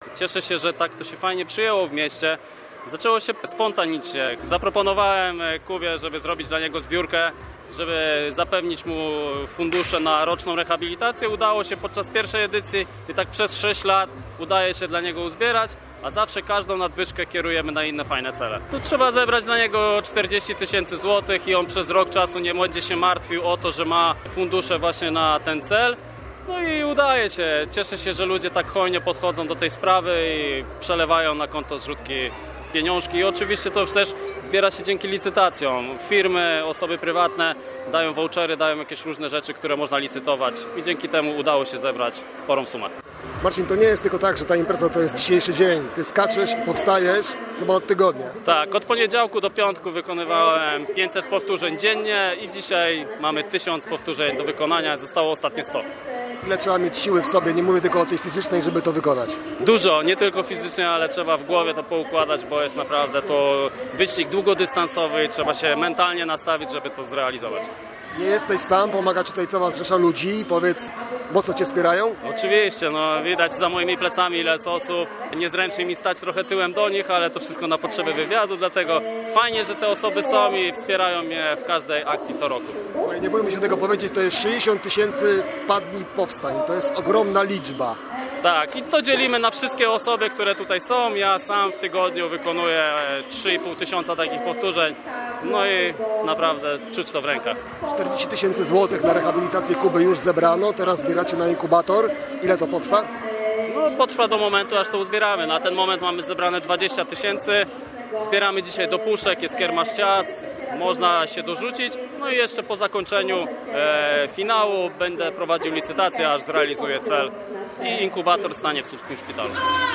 Byliśmy na miejscu. Naszej relacji z tego wydarzenia można posłuchać tu: https